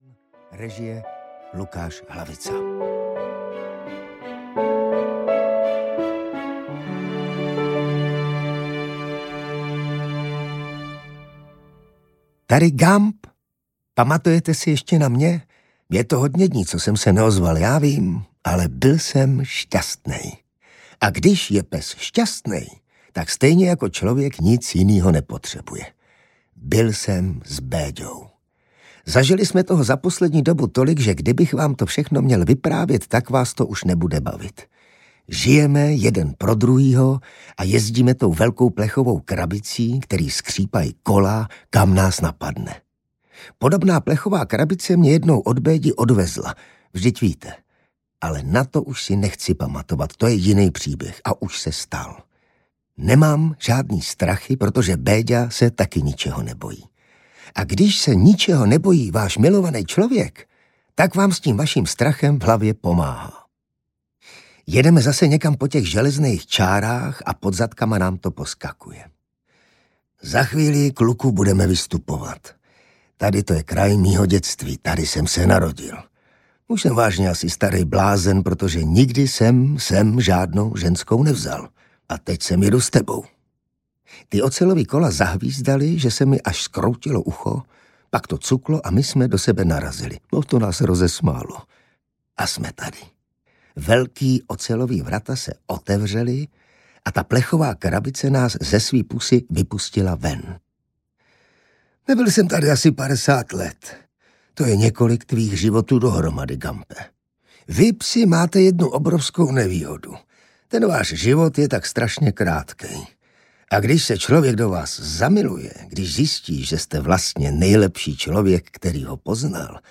GUMP – jsme dvojka audiokniha
Ukázka z knihy
• InterpretIvan Trojan